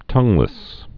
(tŭnglĭs)